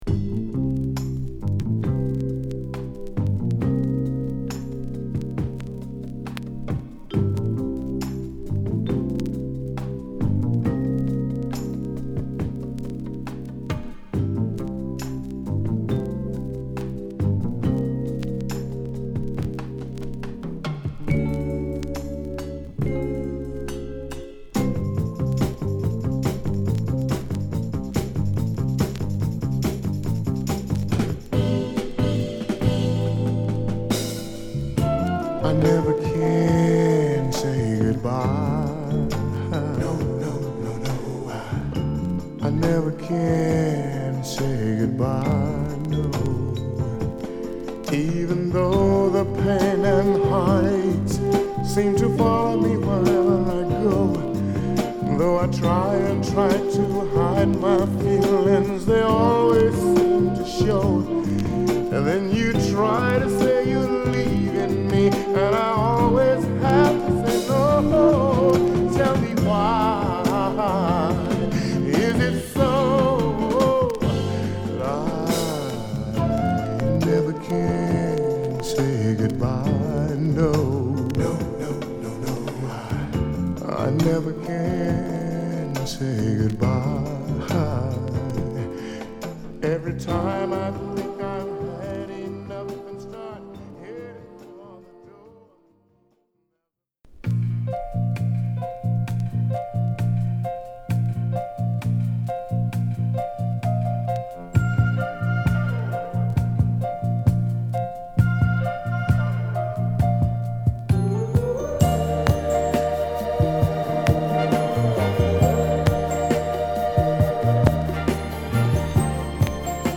＊A2頭に傷小／数回ノイズ入ります。